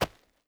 DIRT.2.wav